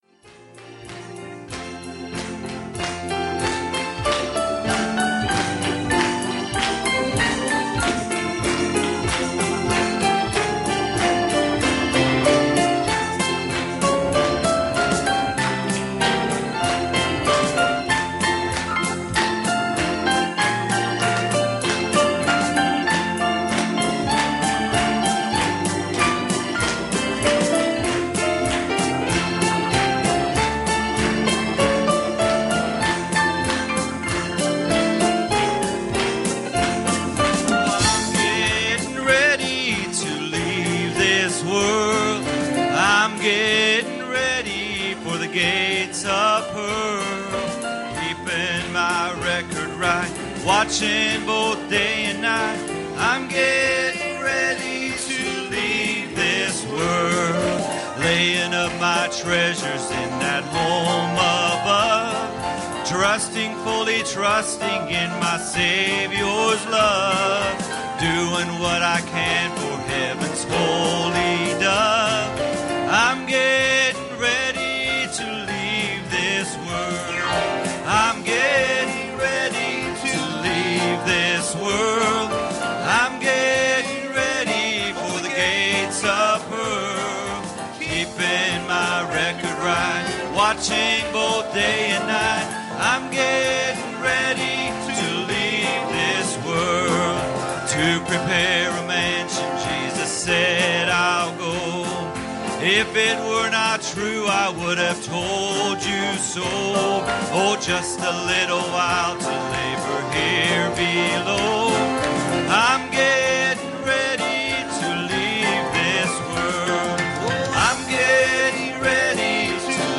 Special Singing